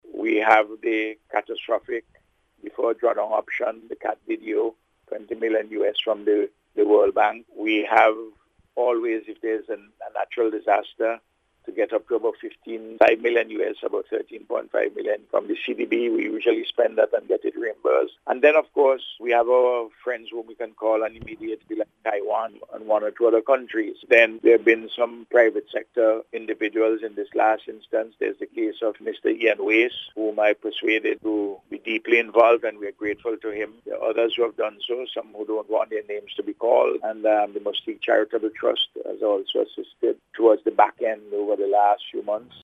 In an interview with NBC News, Prime Minister Gonsalves said the fund is being built bit by bit.